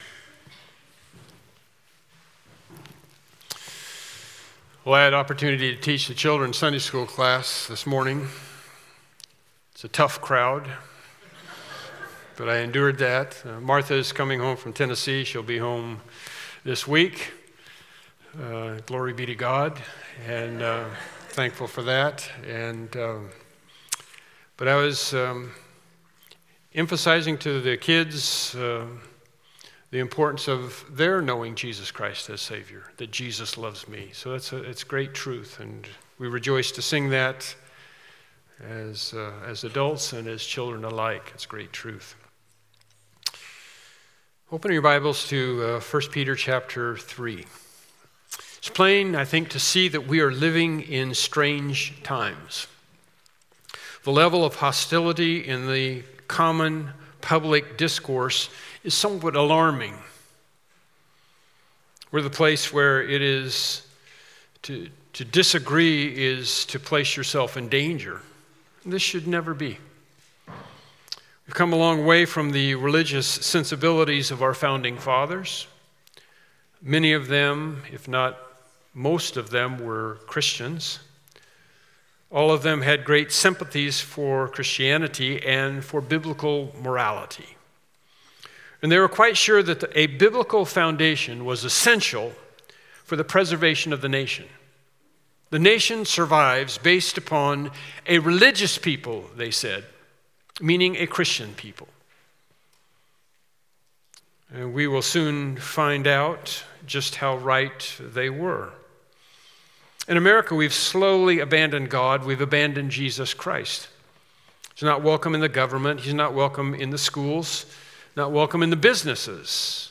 Suffering for Doing Good – HIllcrest Bible Church